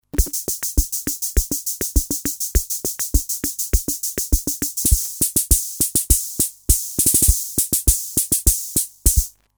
Vintage analog drum machine with preset pattern rhythms and simple trigger pads
demo metronome